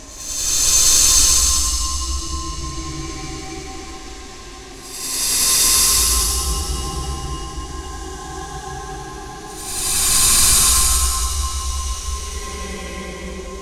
ambient anxious atmosphere creepy dark deep haunted phantom sound effect free sound royalty free Memes